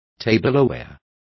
Complete with pronunciation of the translation of tableware.